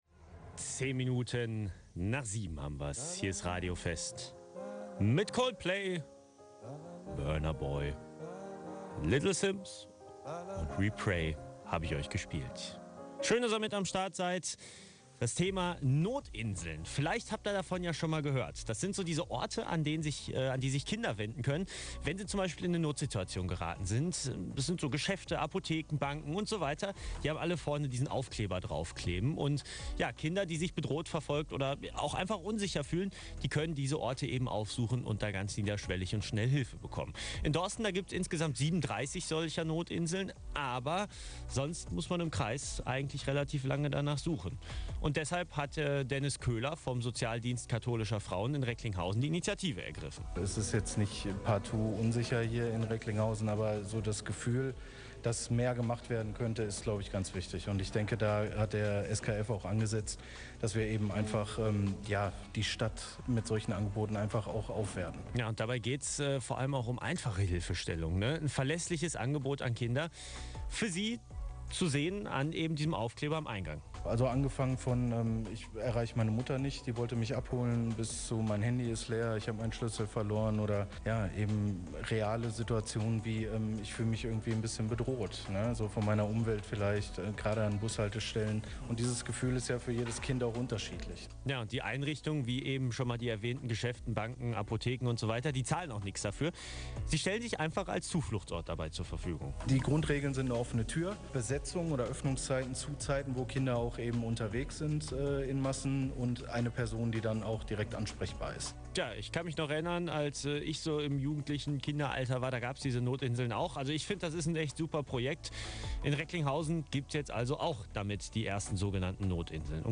Radio-Vest-Mitschnitt-Notinseln-I.mp3